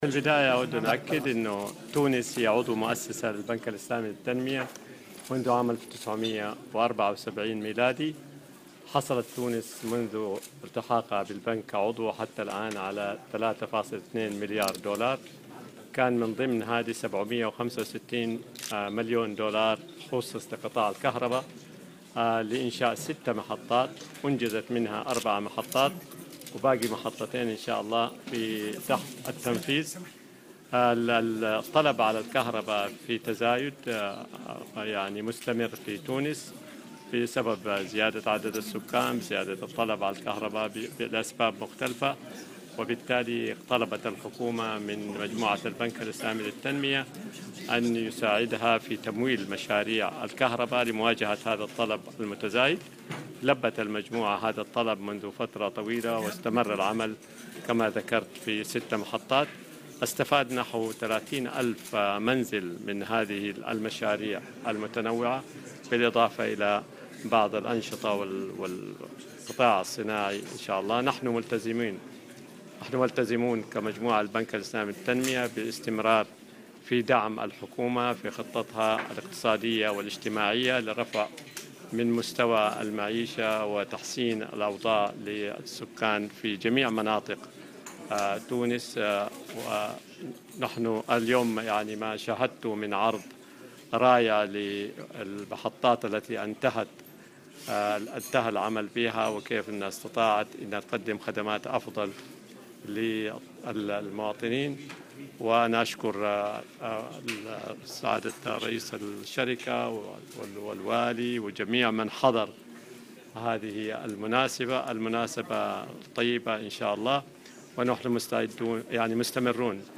وأضاف في تصريح اليوم لـ"الجوهرة أف أم" على هامش زيارته إلى محطة توليد الطاقة سيدي عبد الحميد، أن تونس حصلت منذ التحاقها بالبنك، على تمويلات تقدّر بـ3.2 مليار دولار، من بينها 765 مليون دولار، خصصت لمشاريع إنتاج الكهرباء وإنشاء 6 محطات لتوليد الكهرباء، أنجزت منها إلى حدّ اليوم 4 مشاريع.